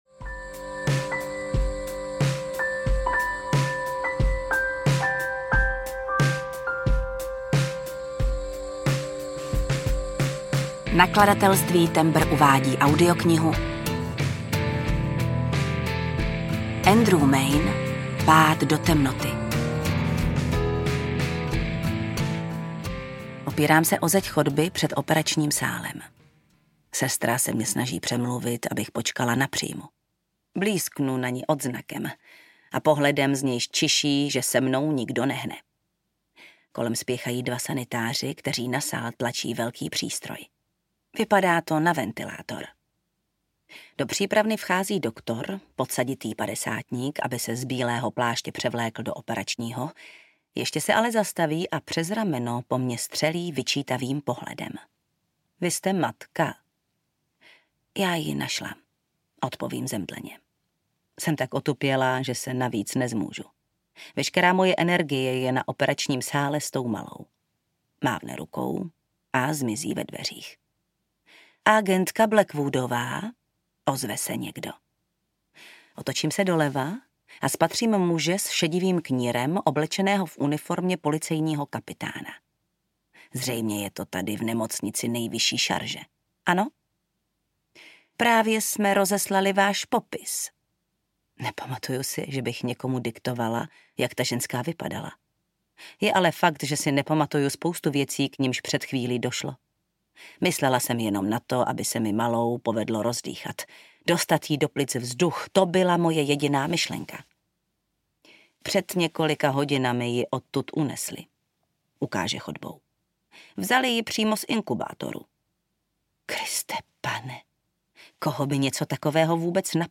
Pád do temnoty audiokniha
Ukázka z knihy
Čte Jana Stryková
Natočeno ve studiu BEEP